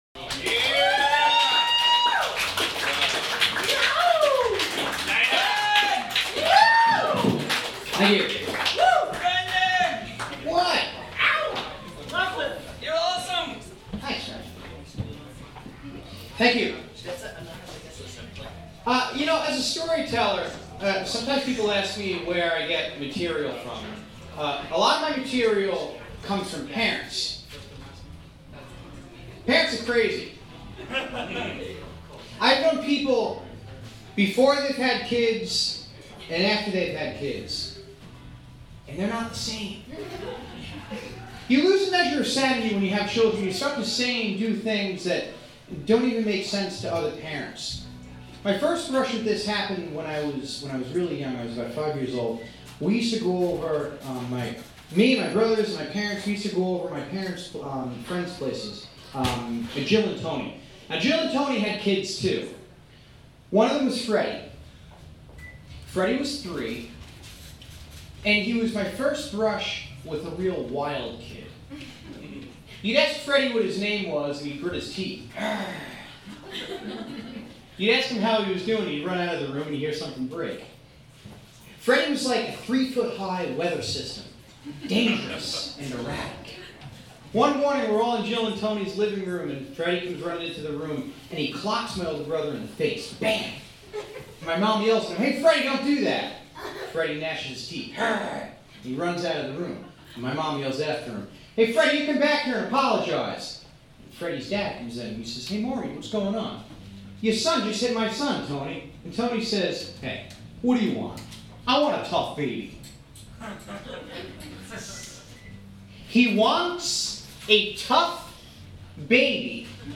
Parents Are Crazy Certifiable but fun Recorded live at Club Bohemia, Cambridge, MA Care Package Mail from my dad isn’t exactly safe Recorded live at the Lizard Lounge, Cambridge MA